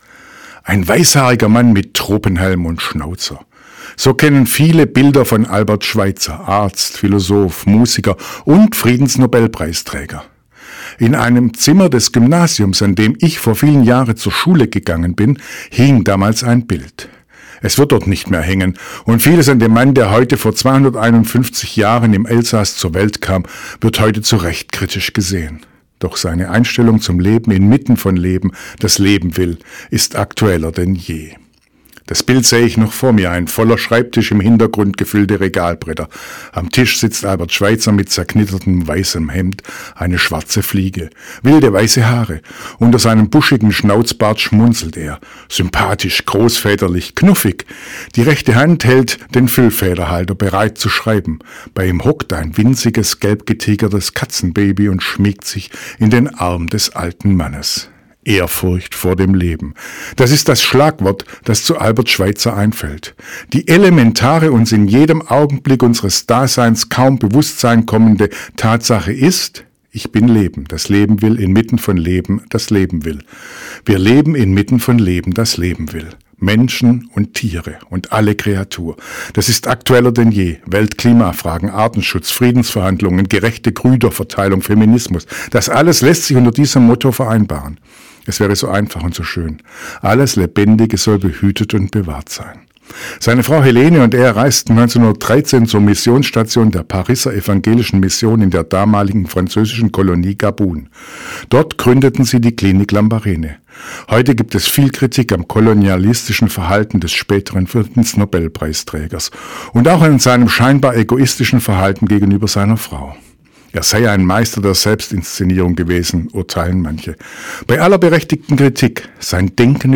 Radioandacht vom 14. Januar